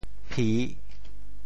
How to say the words 媲 in Teochew？
媲 Radical and Phonetic Radical 女 Total Number of Strokes 13 Number of Strokes 10 Mandarin Reading pì TeoChew Phonetic TeoThew pi3 文 Chinese Definitions 媲〈动〉 匹配;配偶 [marry] 媲,配也。